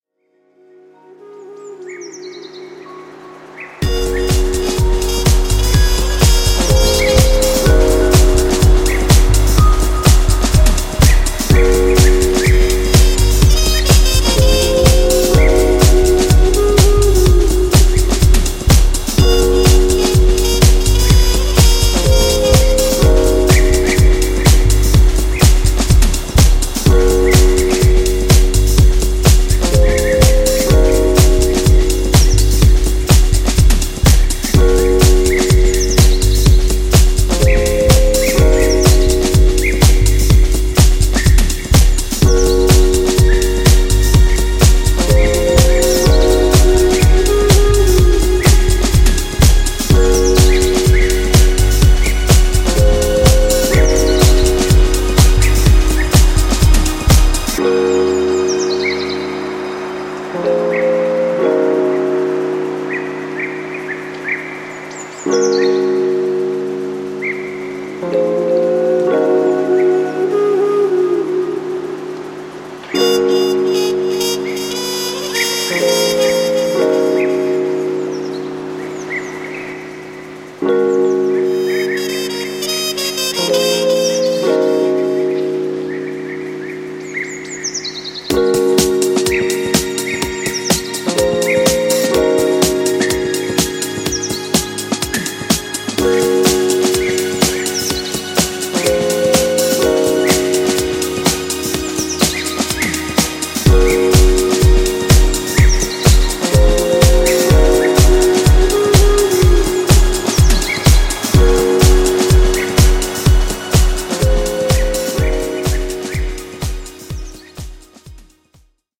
harmonious and glimmering jazz infusions